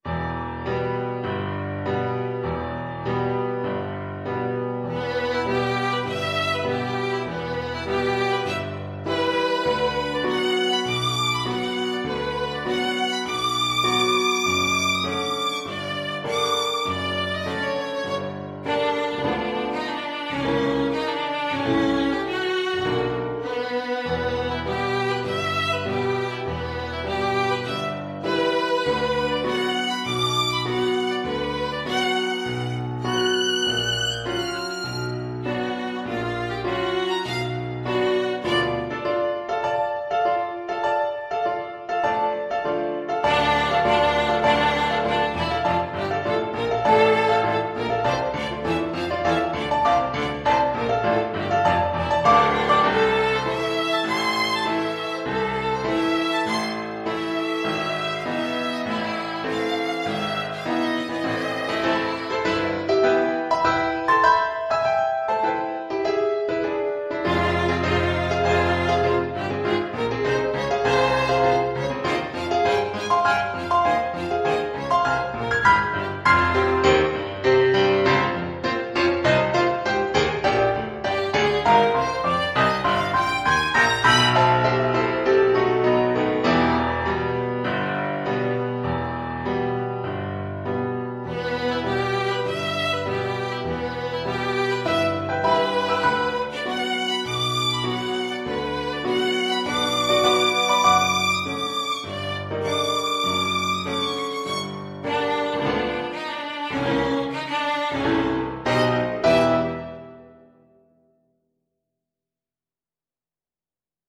Classical (View more Classical Violin Music)